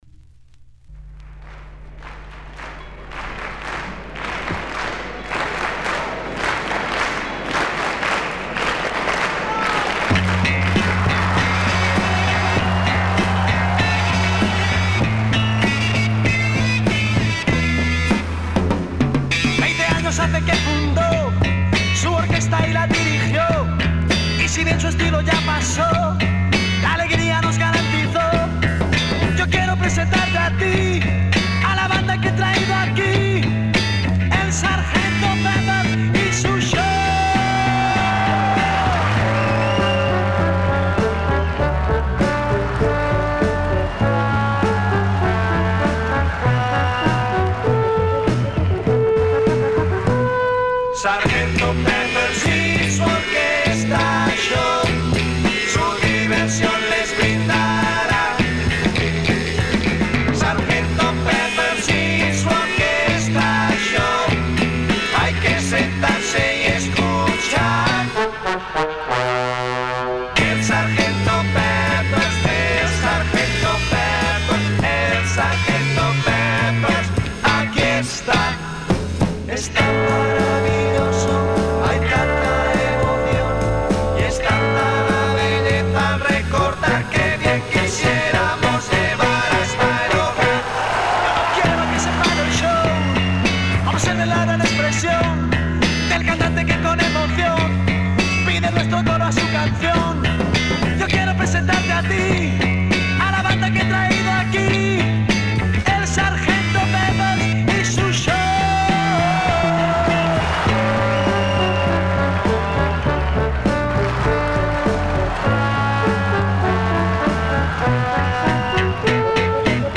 Published February 6, 2011 Garage/Rock Leave a Comment